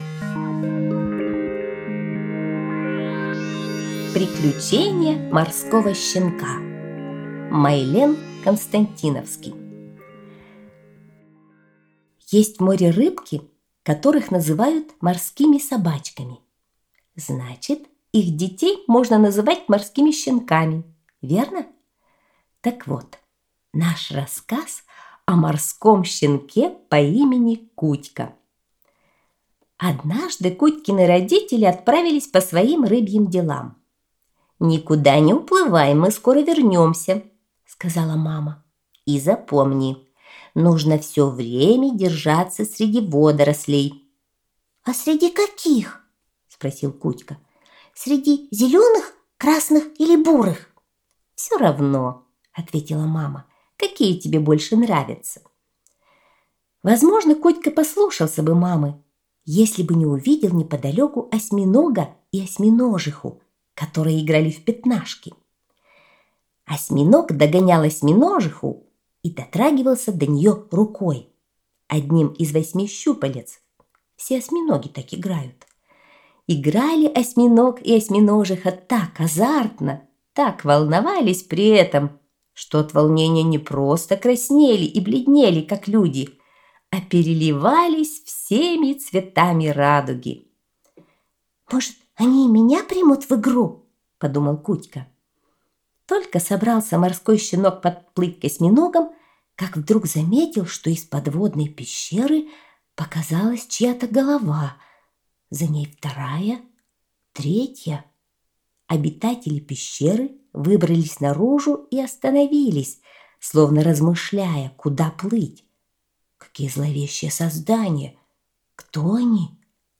Приключения морского щенка - аудиосказка Константиновского М.